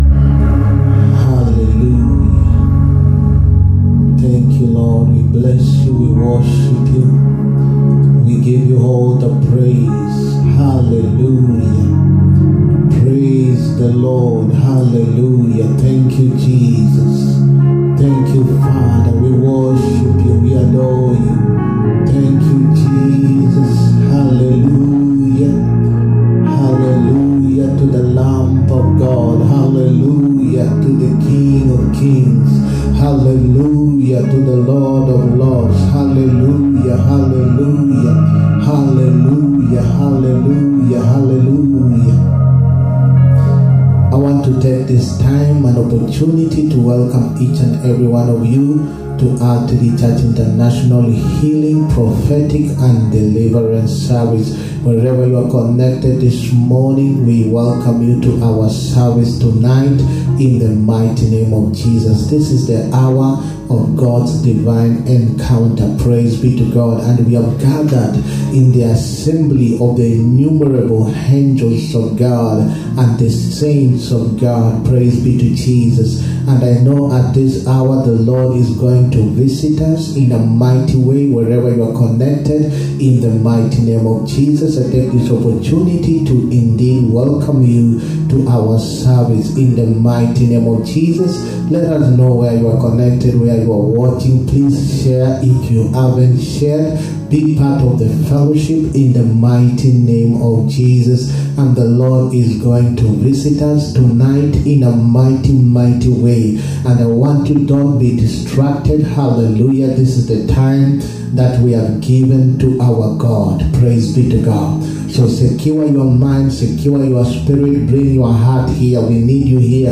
HEALING, PROPHETIC AND DELIVERANCE SERVICE. 23RD SEPTEMBER 2023. PART 1.